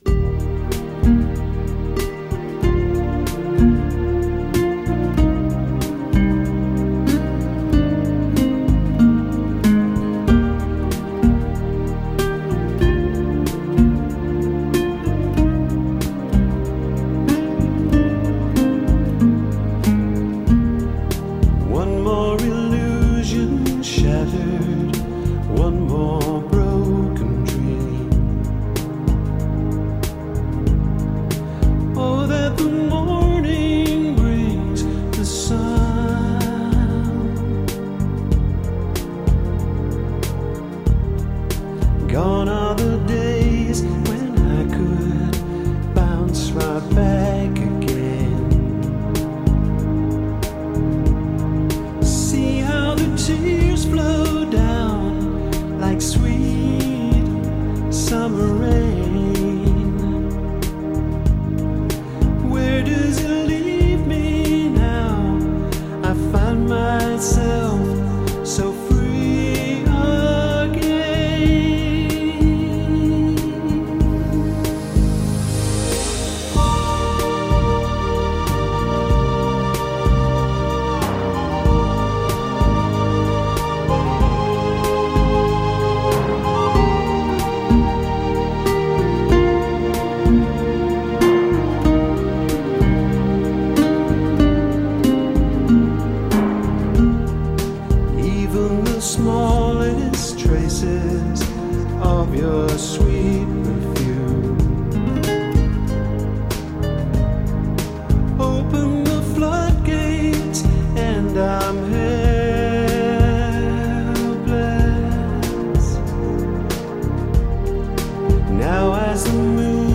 Angelic-voiced
nylon strings
gorgeous ballad